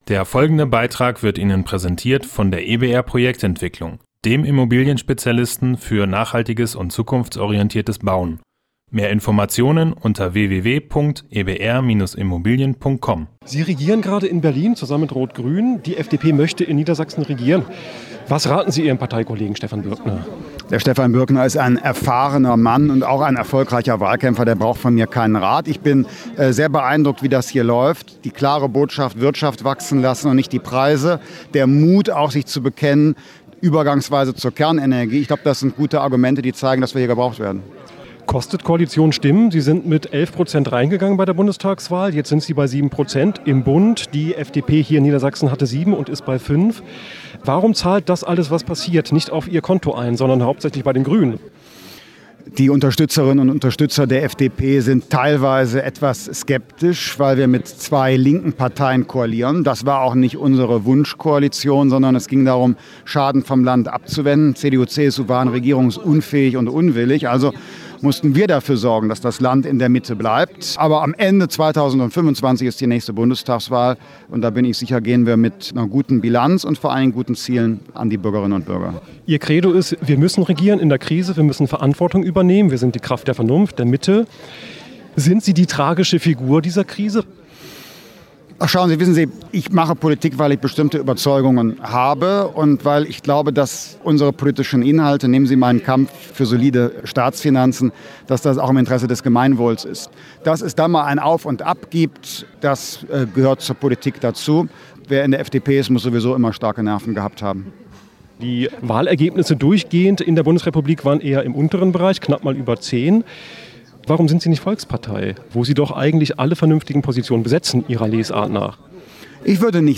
Beiträge > "Besonders charakterfeste Menschen, die entscheiden sich dafür" - Christian Lindner im StadtRadio-Interview - StadtRadio Göttingen